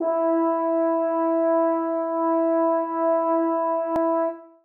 brass / horn-section / samples / E4_mp.mp3
E4_mp.mp3